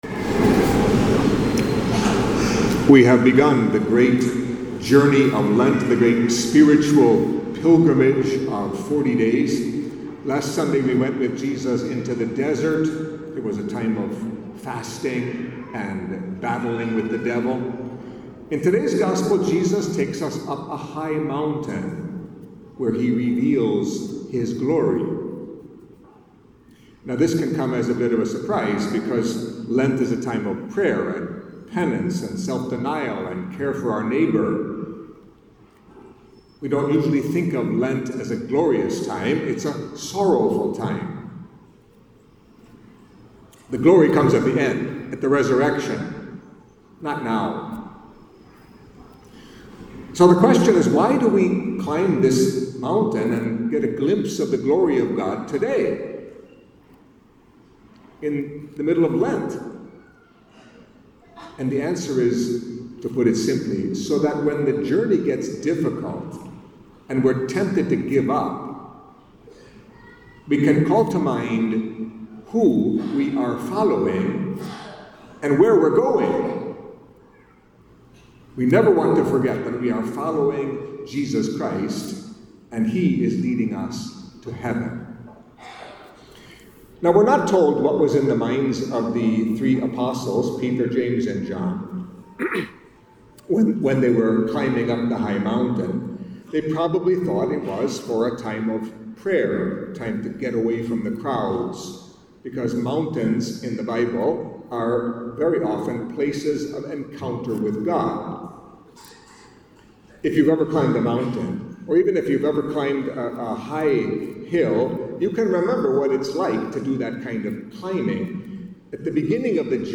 Catholic Mass homily for Second Sunday of Lent